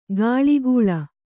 ♪ gāḷigūḷa